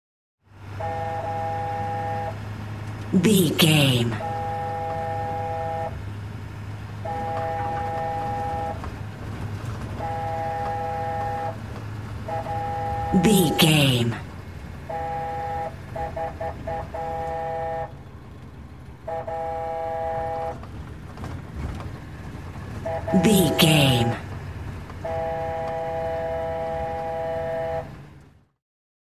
Ambulance Int Drive Engine Horn
Sound Effects
urban
chaotic
emergency